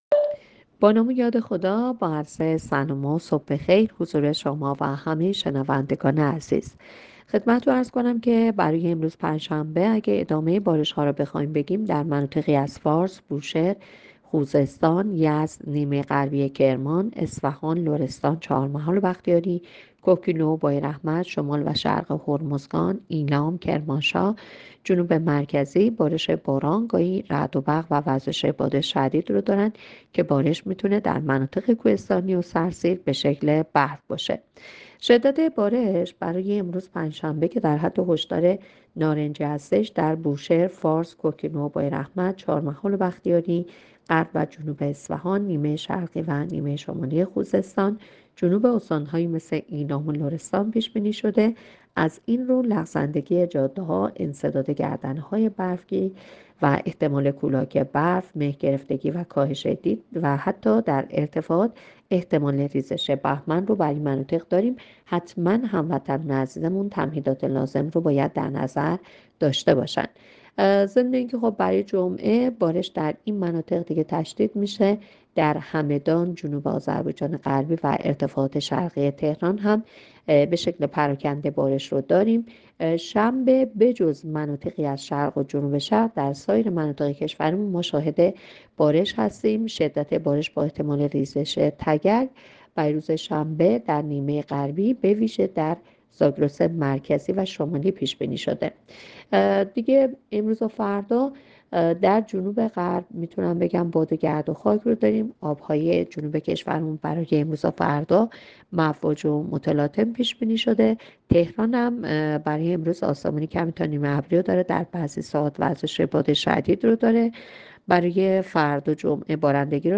گزارش رادیو اینترنتی از آخرین وضعیت ترافیکی جاده‌ها ساعت ۹ شانزدهم اسفند؛